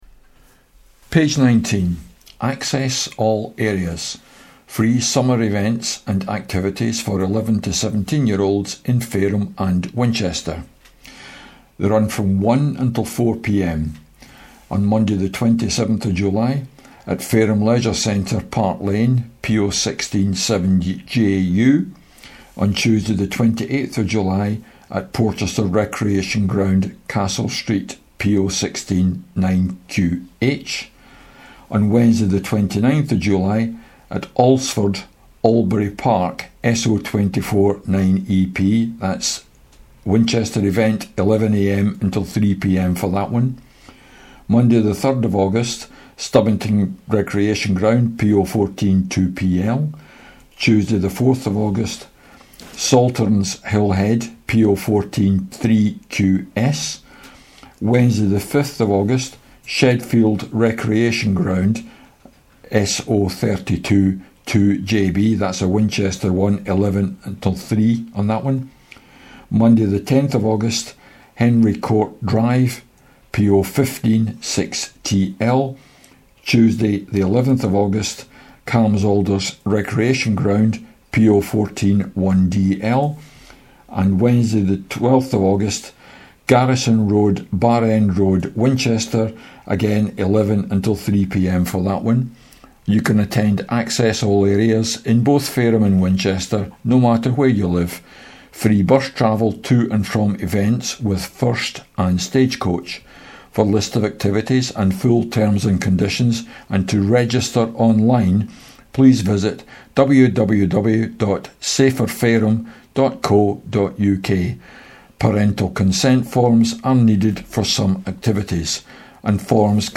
Introduction to presenters & description of front cover. A message from Executive Leader of Fareham Borough Council , Cllr Sean Woodward.